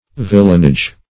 Villanage \Vil"lan*age\ (?; 48), n. [OF. villenage, vilenage.